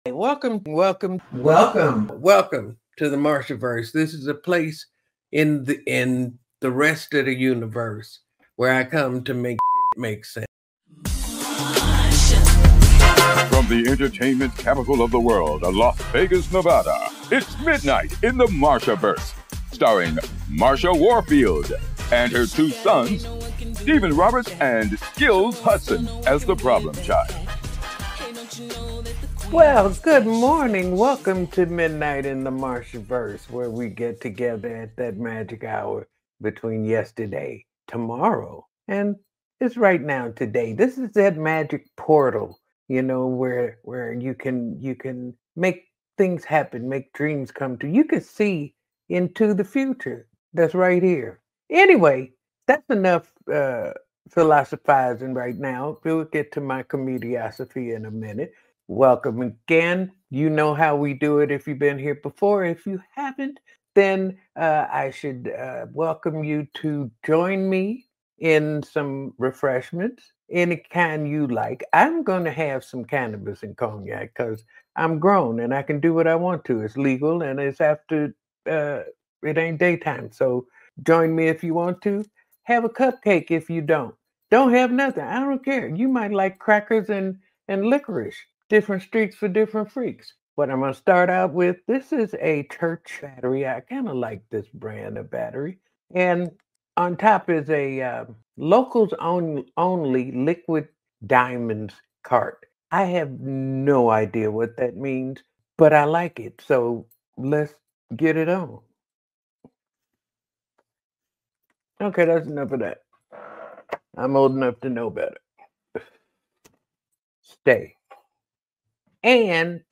🎤🔥 From iconic collaborations with Sylvester and C+C Music Factory to unforgettable moments with Luther Vandross, Martha shares her journey as a trailblazer who gave voice and visibility to generations. Don’t miss this soulful conversation that blends comedy, history, and music magic.